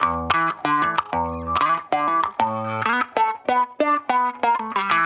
Index of /musicradar/sampled-funk-soul-samples/95bpm/Guitar
SSF_StratGuitarProc1_95E.wav